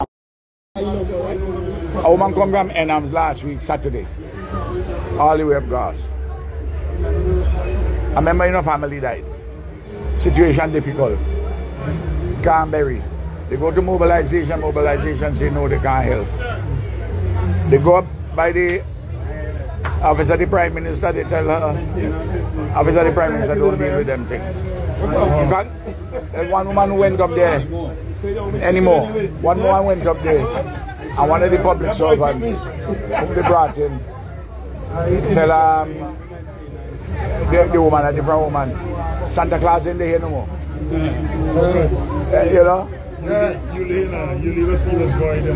Speaking during a Facebook livestream, the former prime minister cites alleged incidents following his party’s crushing 14–1 election defeat.
| Former Prime Minister Dr. Ralph Gonsalves speaks during a Facebook livestream.